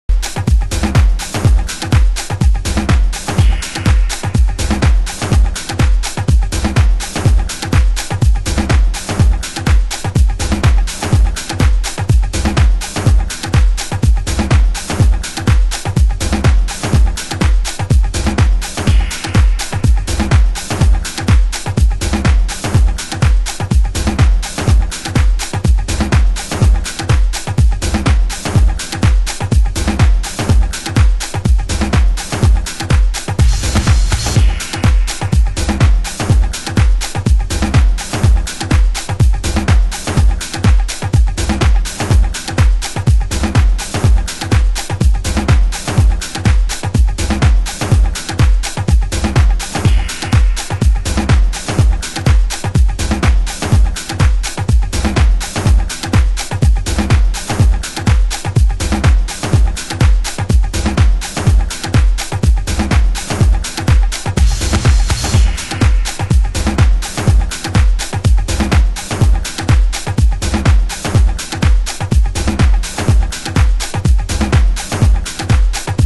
盤質：B面に軽いスレ傷有（試聴箇所になっています）/少しチリパチノイズ有